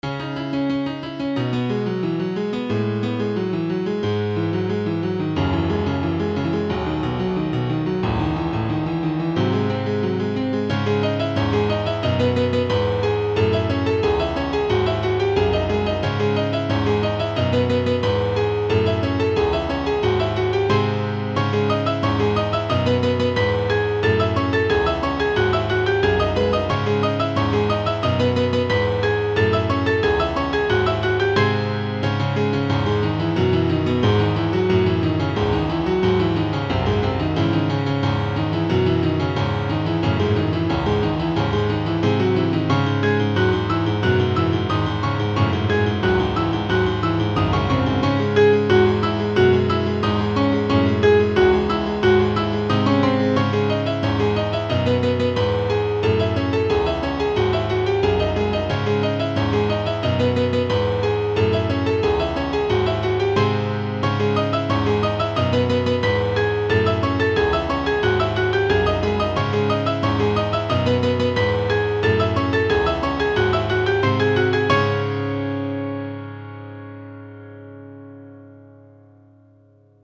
arrangeconcpianoonly.mp3